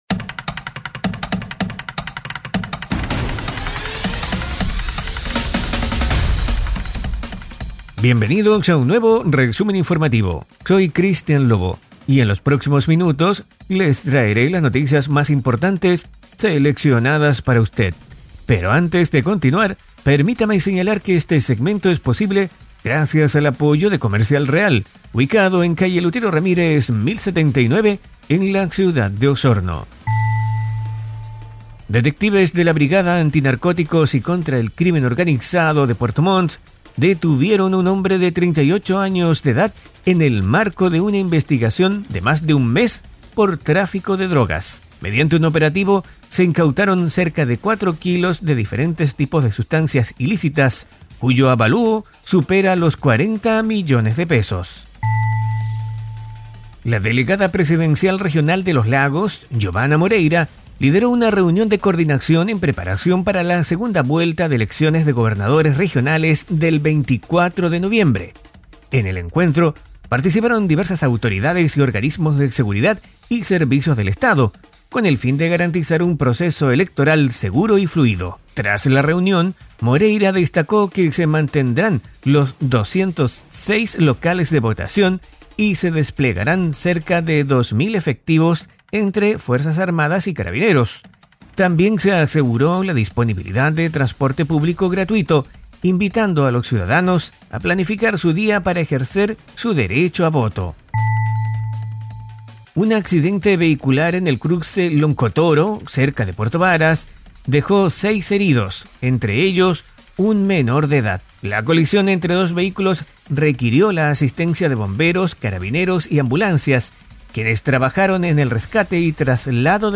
Resumen Informativo 🎙 Podcast 12 de noviembre de 2024